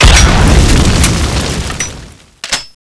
cannonex_shoota.wav